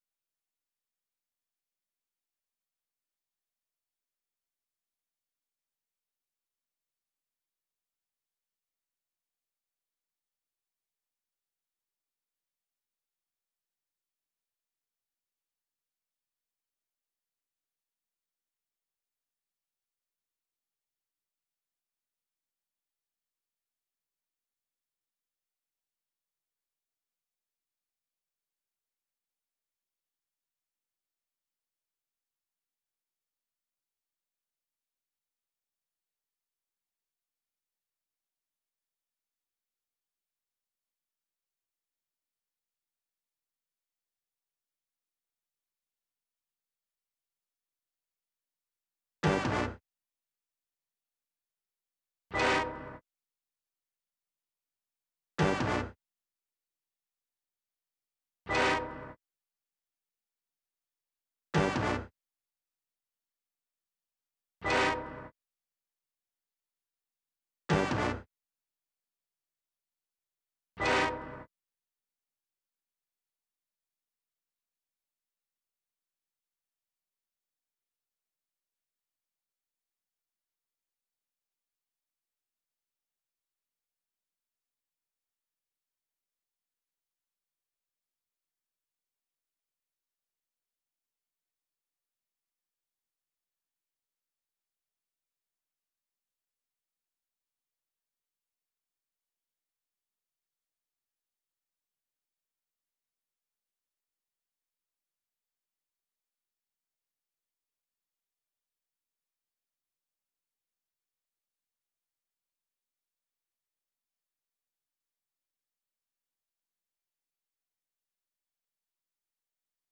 sample Horns -78bpm.wav